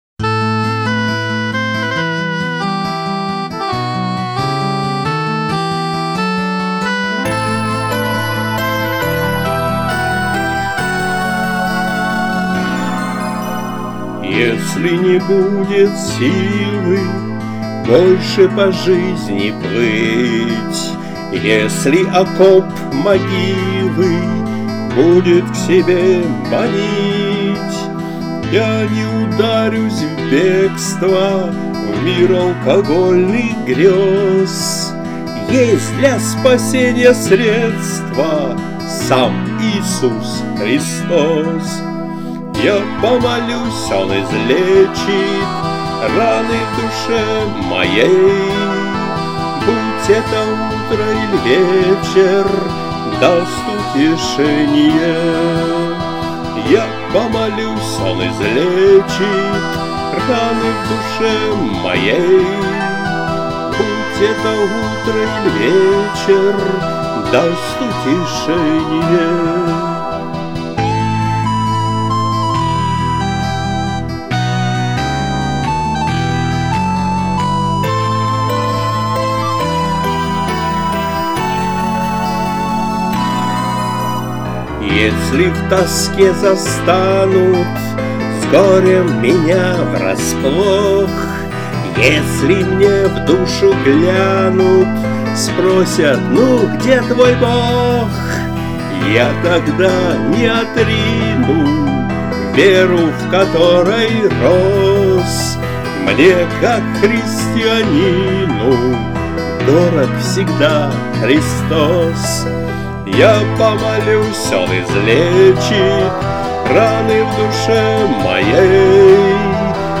Христианские музыкальные песни